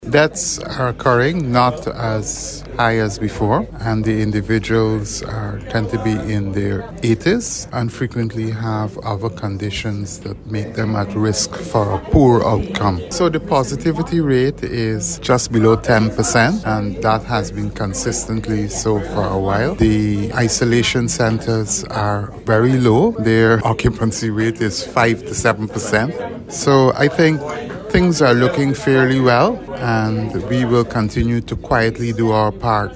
Chief Medical Officer, Dr. Kenneth George.